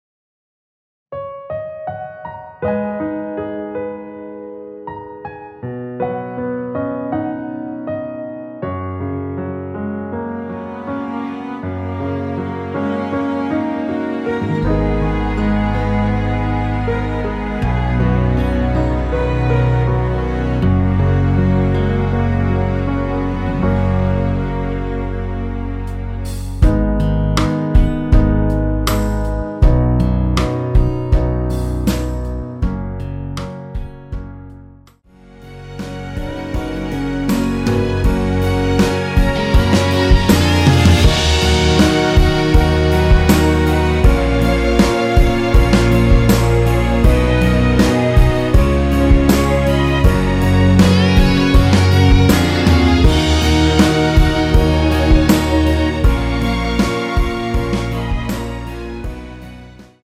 MR입니다.
F#
앞부분30초, 뒷부분30초씩 편집해서 올려 드리고 있습니다.
중간에 음이 끈어지고 다시 나오는 이유는